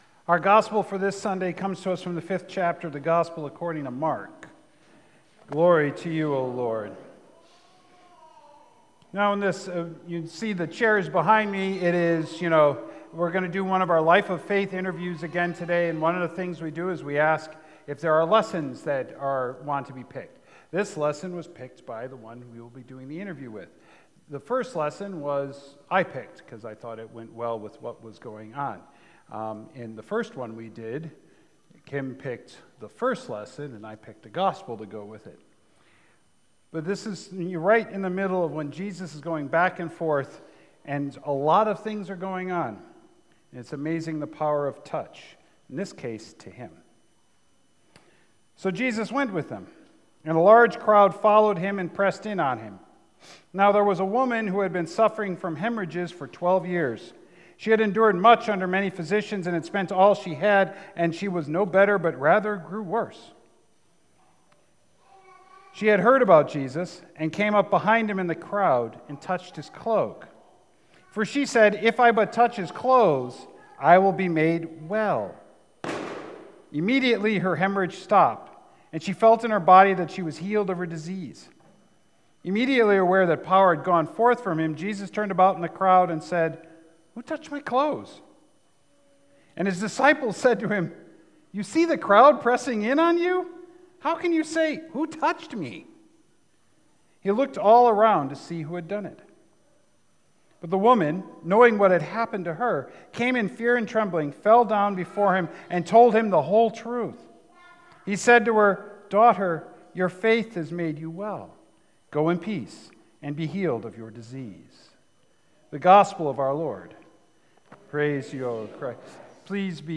interview style sermon